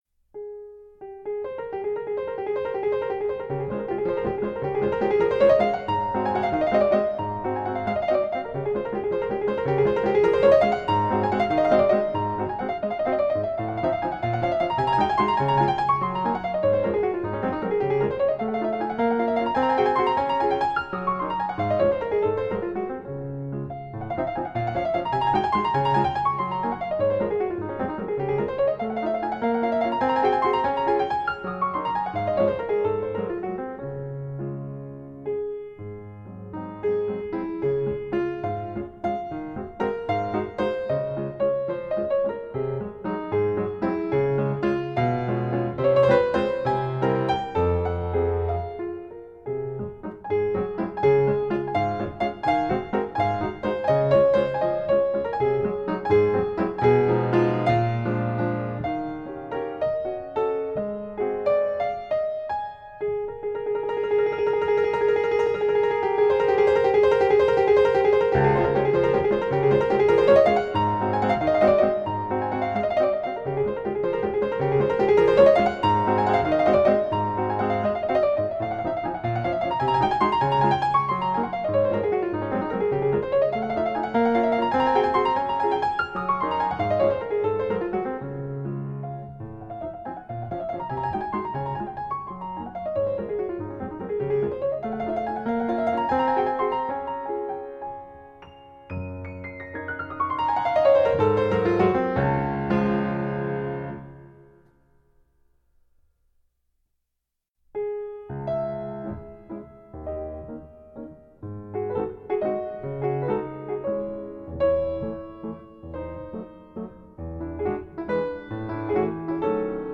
Valse in A-flat major Piano version
Classical Piano